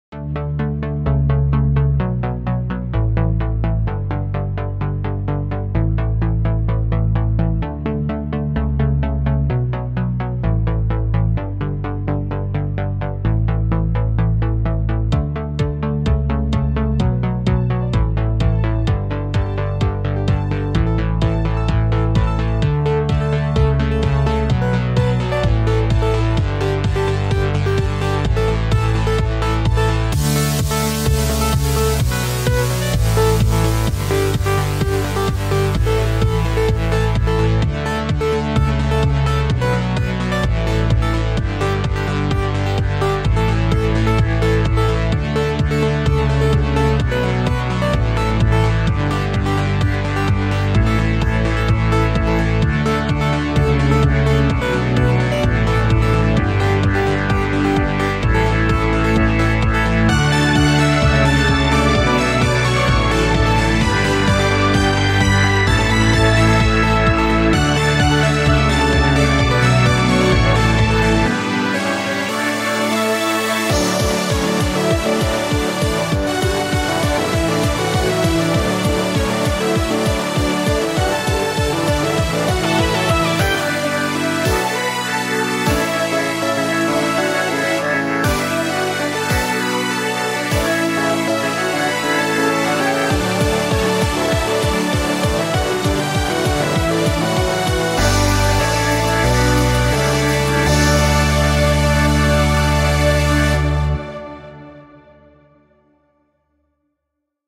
Featuring those chords of life from progressive house songs
organ